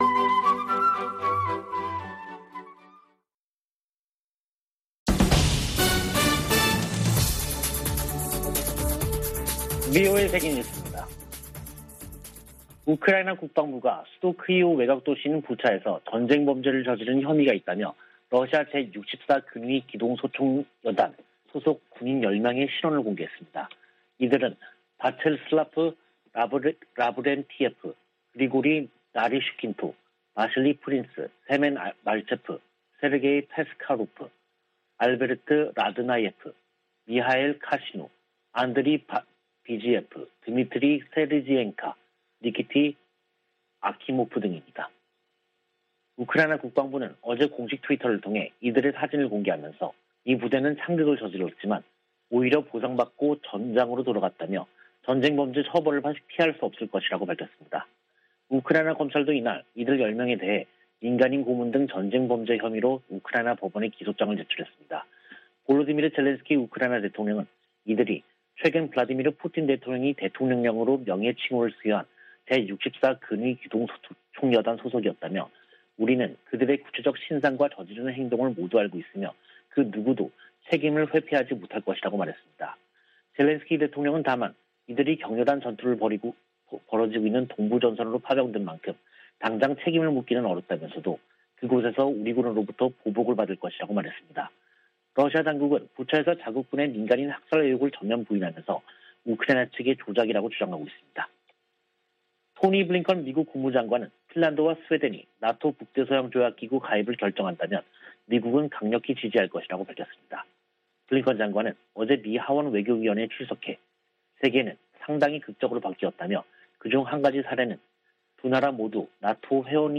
VOA 한국어 간판 뉴스 프로그램 '뉴스 투데이', 2022년 4월 29일 3부 방송입니다. 다음 달 미한 정상회담에서는 동맹강화와 대북공조 등이 중점 논의될 것으로 보입니다. 김정은 북한 국무위원장의 ‘핵무력 강화’ 발언에 대해 미국은 핵 억지력과 미사일 방어망을 강화해야 한다고 상원 외교위원회 공화당 간사가 강조했습니다. 북한 문제에 대한 중국의 협력을 기대하기 어렵다고 전 국무부 동아시아태평양 차관보가 밝혔습니다.